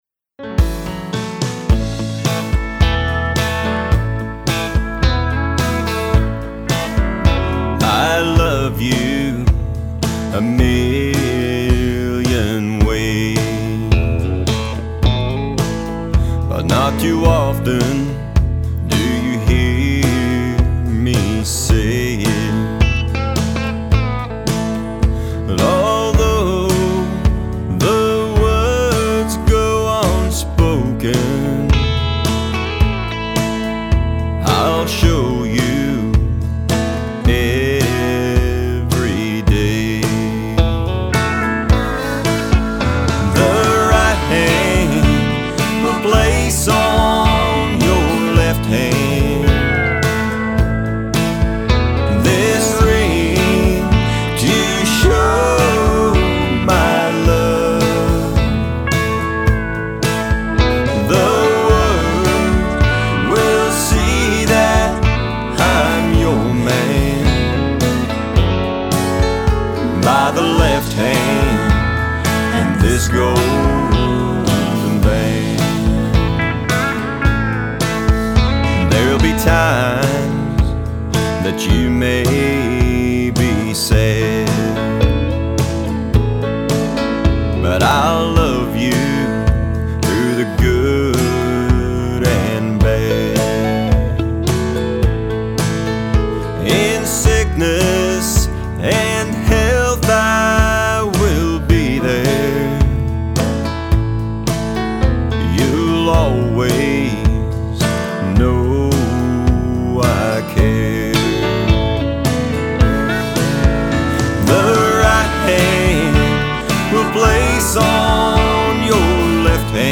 beautiful Love song